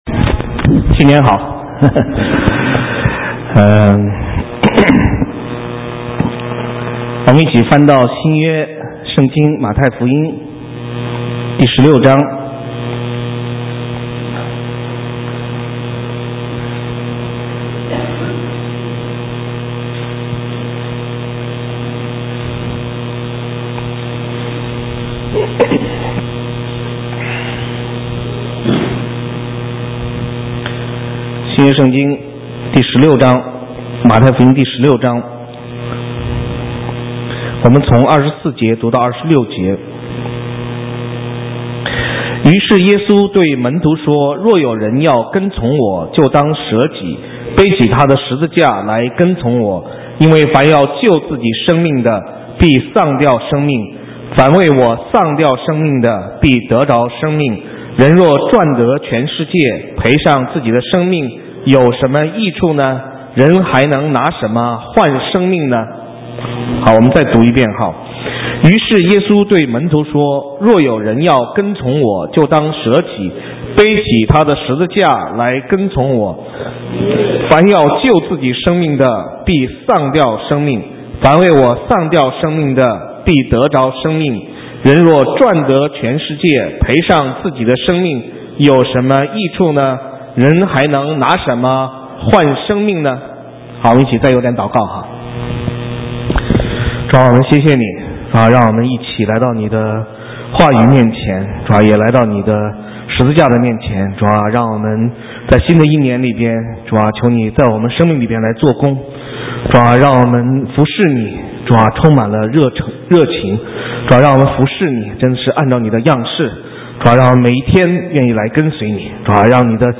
神州宣教--讲道录音 浏览：通往蒙福的道路 (2011-01-09)